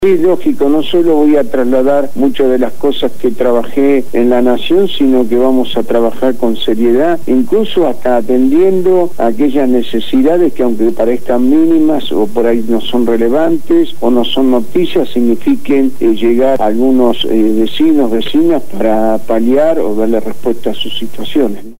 Lo afirmó Juan Carlos Dante Gullo, diputado nacional del Frente Para la Victoria y candidato a Legislador de la Ciudad de Buenos Aires por esa fuerza política en las próximas elecciones del 10 de julio de 2011, donde también se elige Jefe y Vicejefe de Gobierno y a los integrantes de las Juntas Comunales en las 15 Comunas porteñas, quien fue entrevistado en el programa «Punto de Partida» de Radio Gráfica FM 89.3